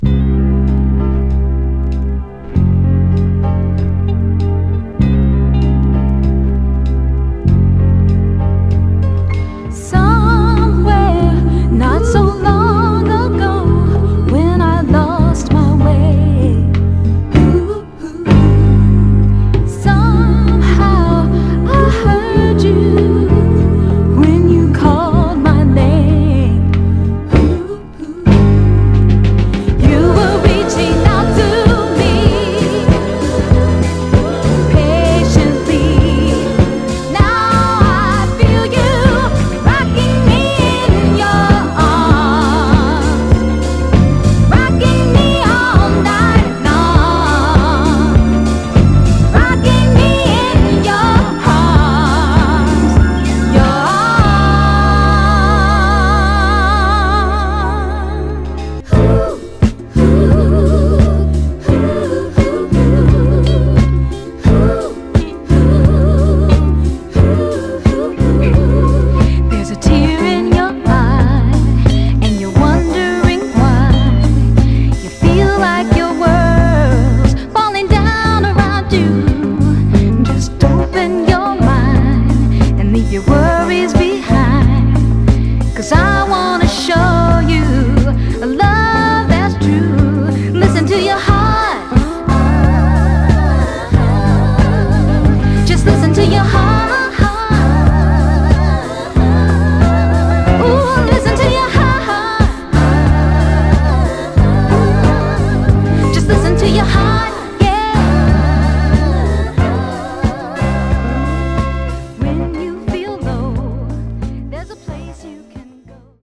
盤はエッジに僅かなヒート跡あり、軽く針が揺れてA面頭で数回小さくザッと鳴ります。
※試聴音源は実際にお送りする商品から録音したものです※